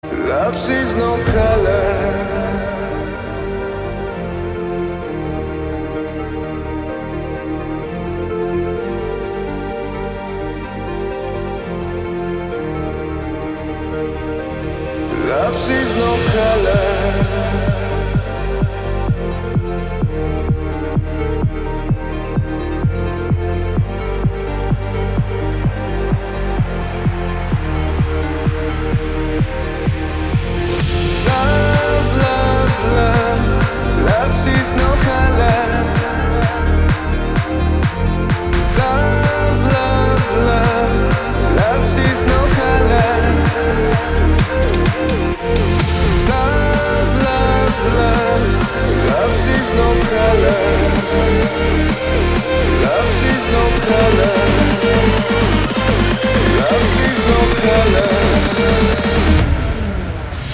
• Качество: 256, Stereo
мужской вокал
Electronic
электронная музыка
спокойные
нарастающие
progressive house